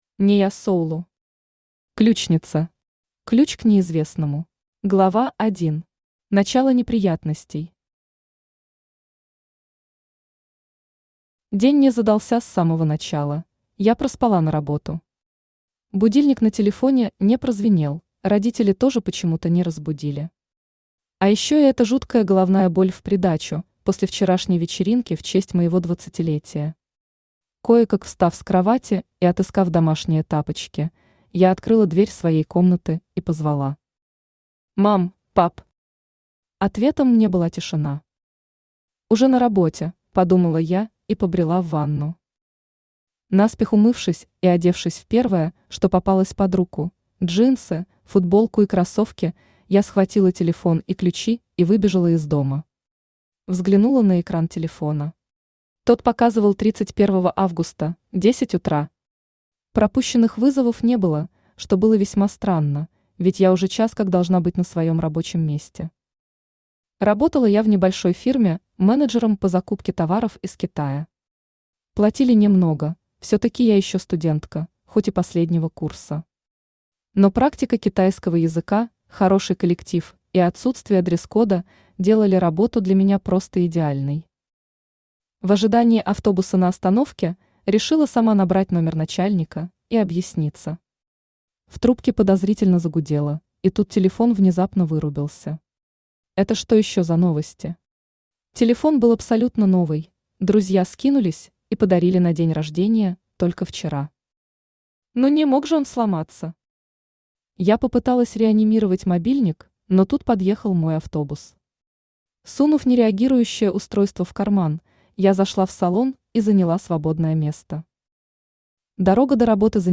Ключ к неизвестному Автор Нея Соулу Читает аудиокнигу Авточтец ЛитРес.